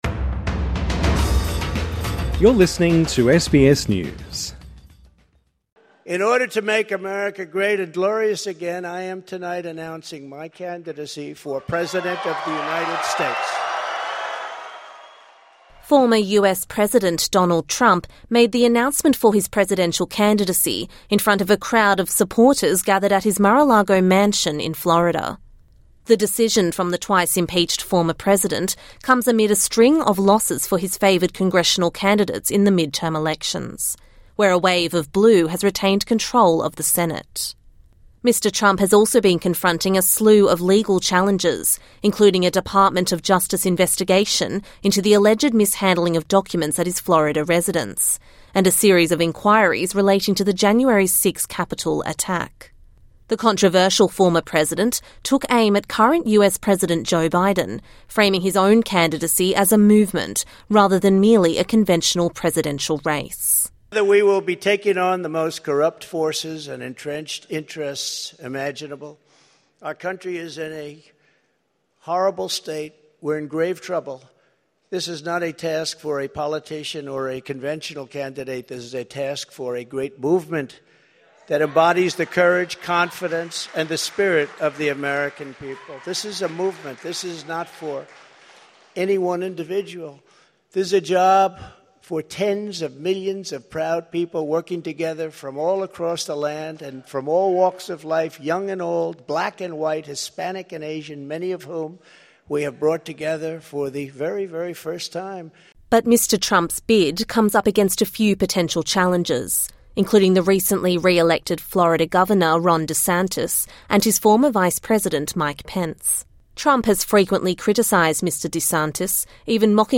The twice-impeached former president, Donald Trump, has announced he will run for the 2024 election in the United States. Speaking in front of a crowd of supporters in his Florida Mar-a-Lago mansion, Mr Trump heralded the return of the 'Make America Great Movement'.